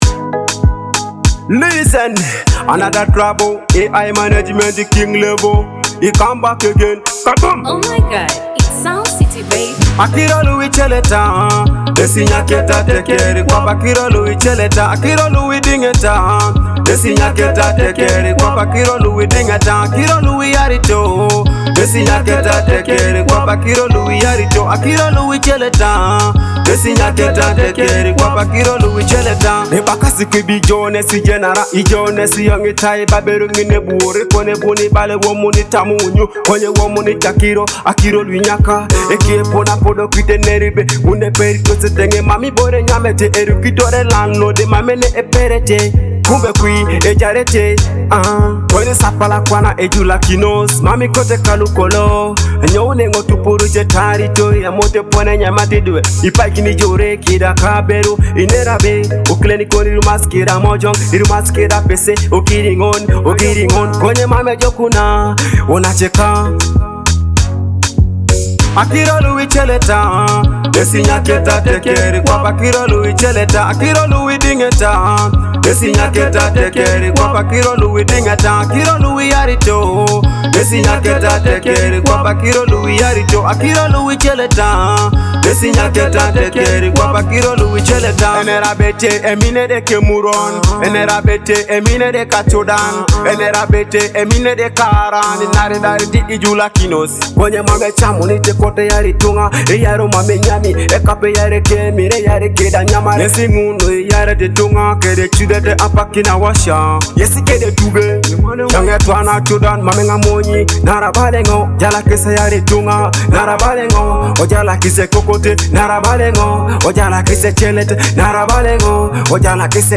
a powerful Afrobeat–Teso fusion hit
energetic Afrobeat drums and smooth Teso melodies